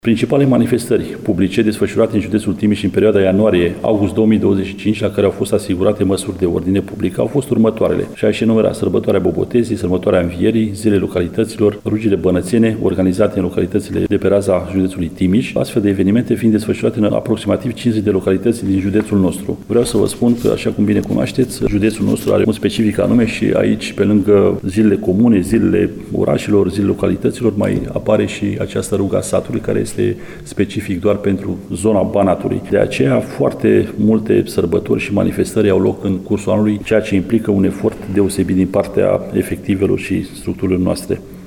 Comandantul Inspectoratului Județean de Jandarmerie Timiș, colonel Marian Toma, spune că misiunile din județ sunt aparte datorită specificului religios.